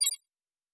pgs/Assets/Audio/Sci-Fi Sounds/Interface/Digital Click 01.wav at master
Digital Click 01.wav